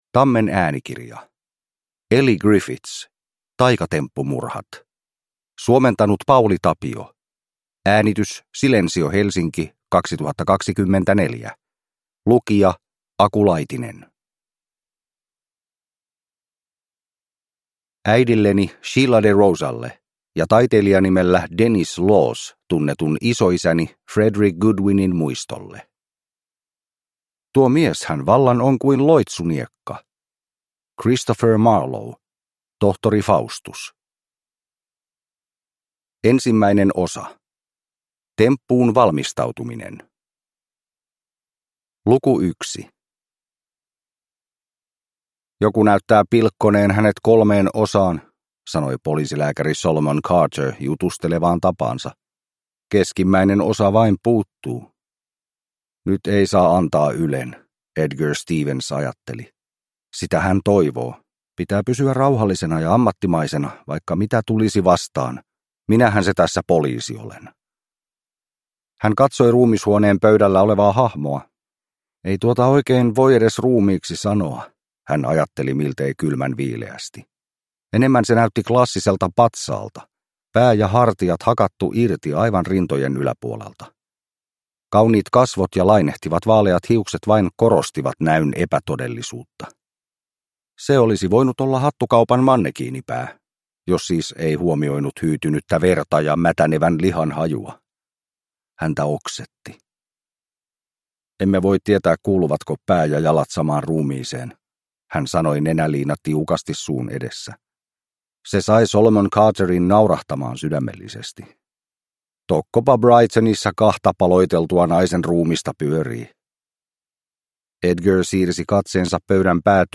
Taikatemppumurhat – Ljudbok